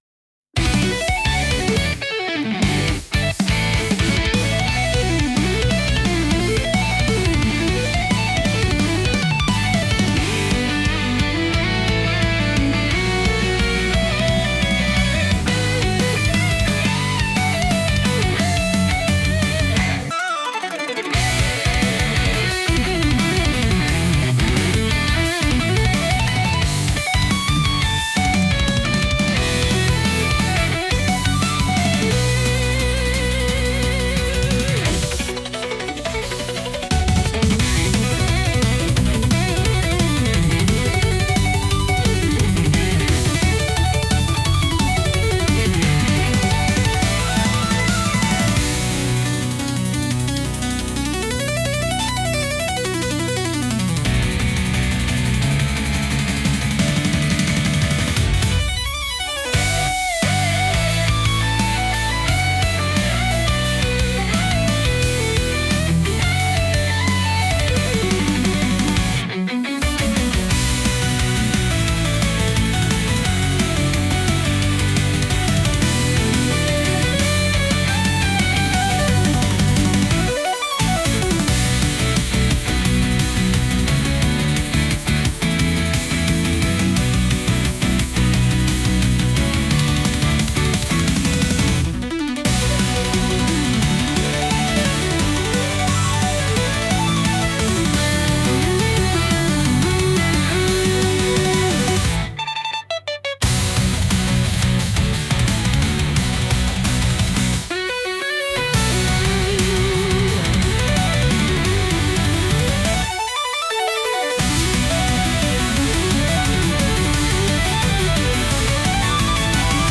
手に汗握る熱い戦いのためのBGM